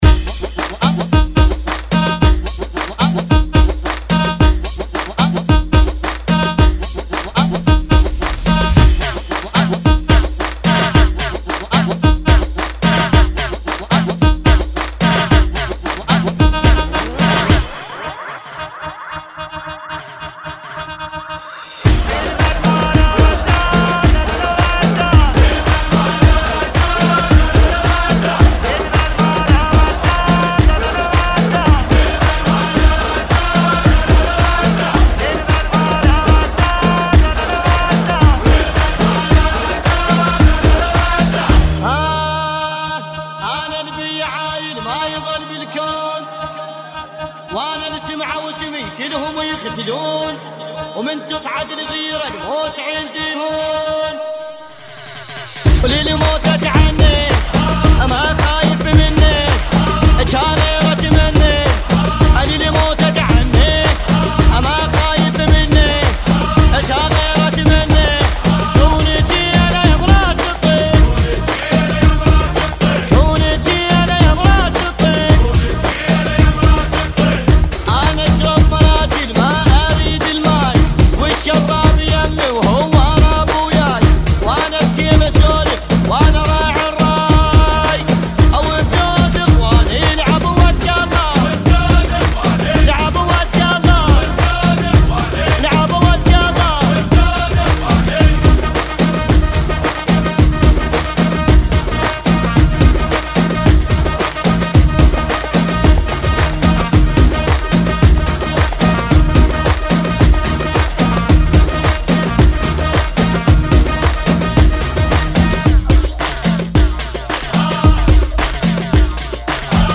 [ 110 Bpm ]